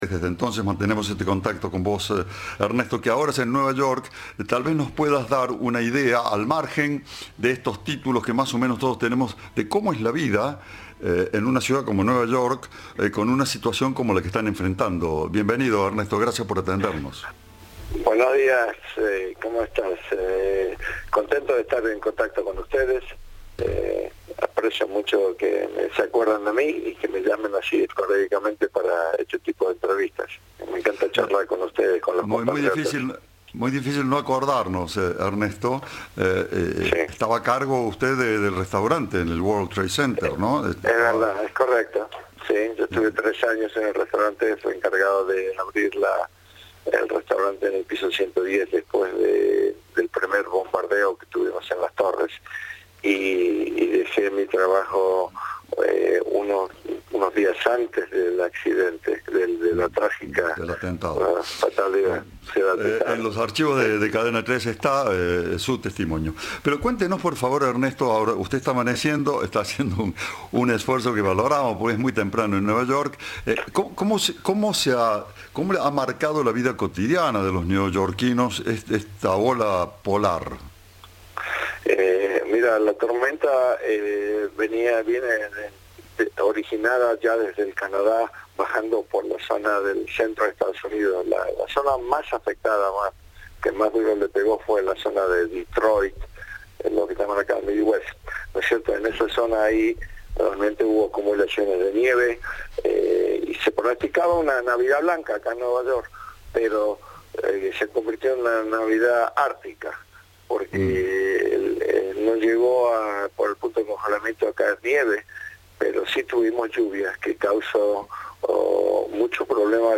Audio. "Tuvimos un Navidad ártica" contó un argentino en Nueva York